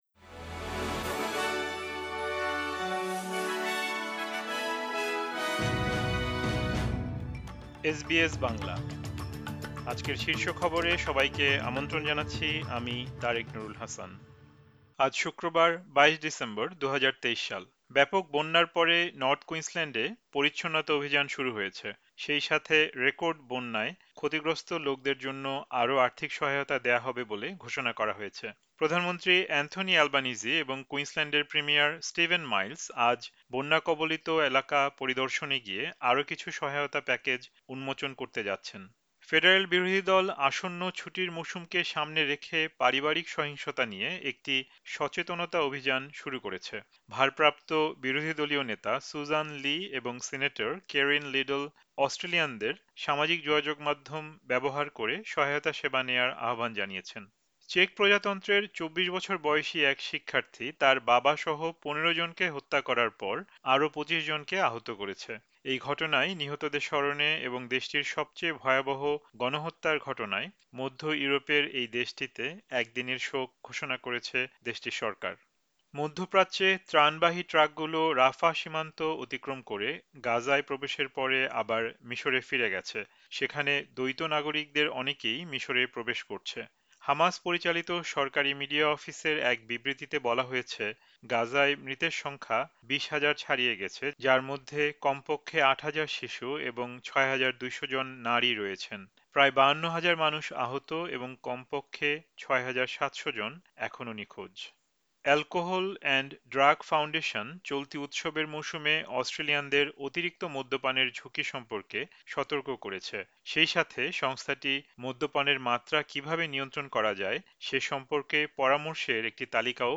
এসবিএস বাংলা শীর্ষ খবর: ২২ ডিসেম্বর, ২০২৩